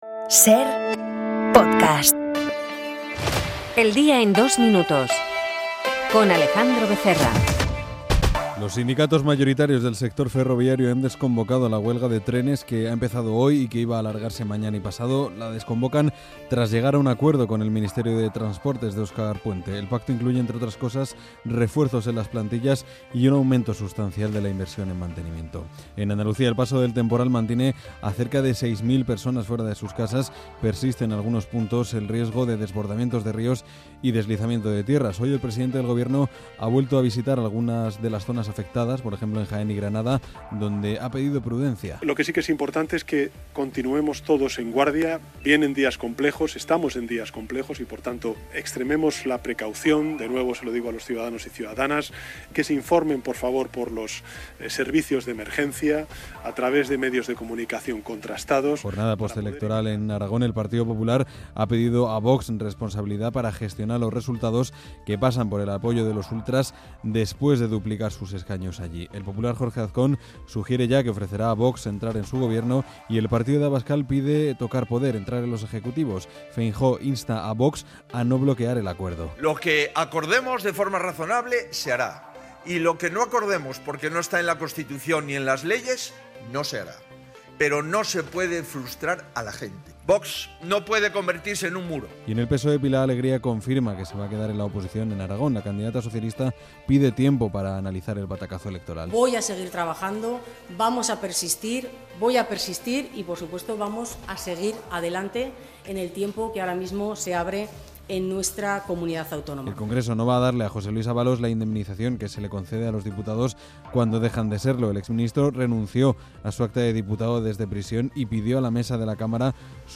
El resumen de las noticias de hoy